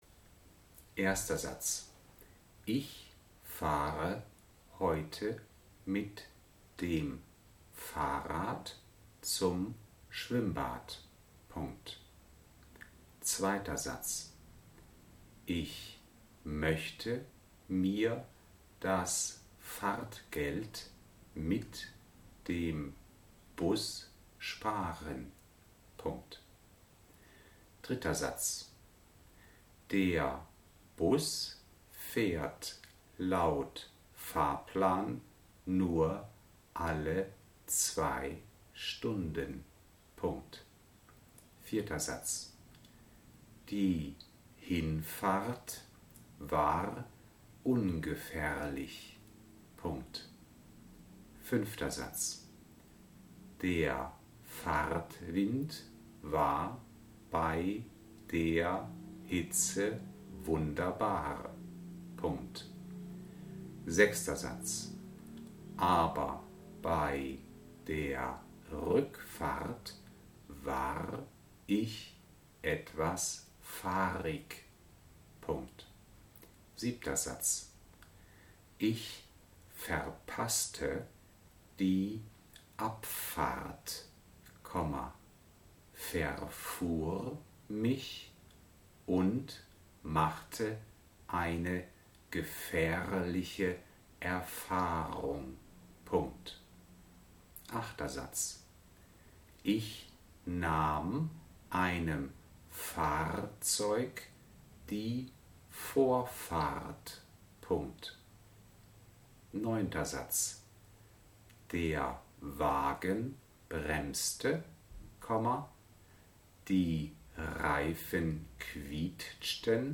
1. DIKTAT  Wortstamm: fahr (docx) (pdf)